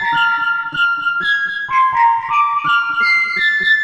cch_synth_whistle_125_Bb.wav